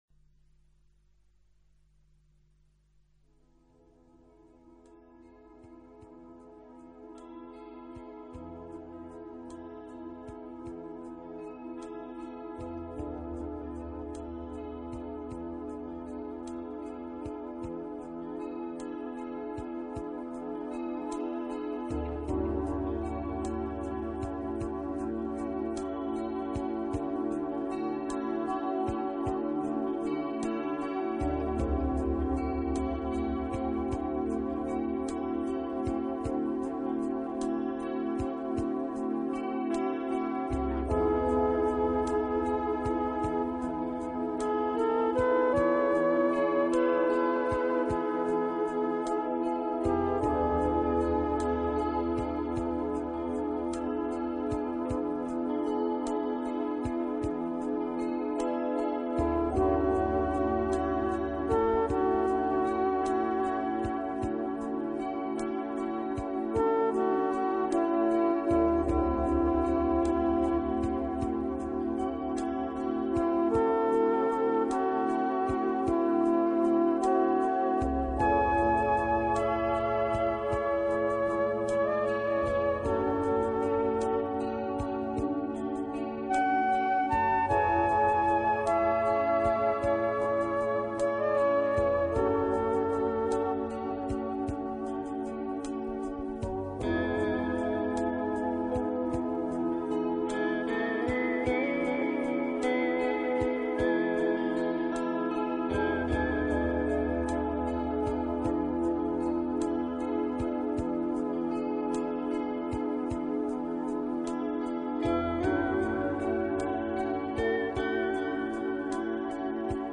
音乐类型:  New Age